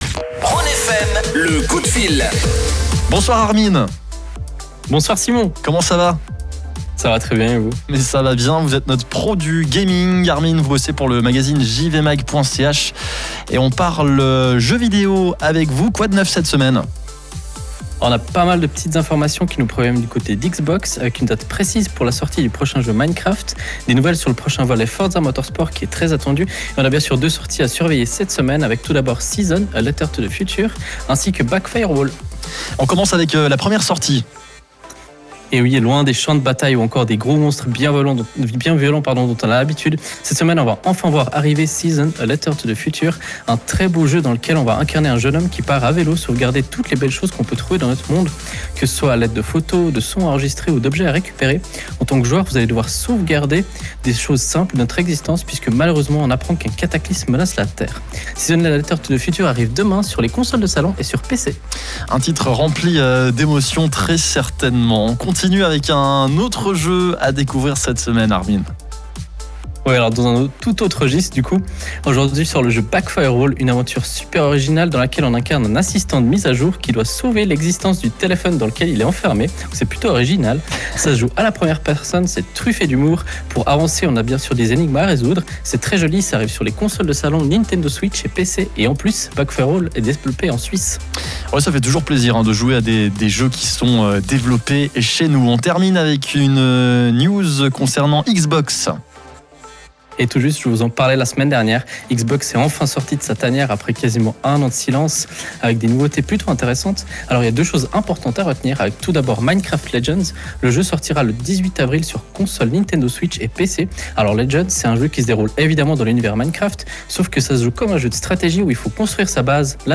Un espace dédié à l’actualité du jeu vidéo qui se déroule en direct de manière hebdomadaire. Au programme cette semaine; les annonces par Xbox au sujet du prochain jeu Minecraft ou encore Forza Motorsport et sa date confirmée pour 2023.
Le direct est à réécouter via le flux qui se trouve juste en dessus.